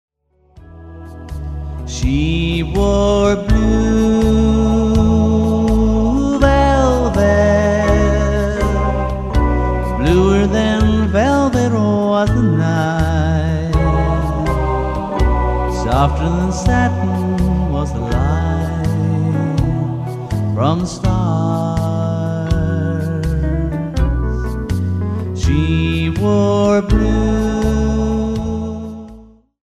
specialising in Country Music and Classic Rock ‘n’ Roll.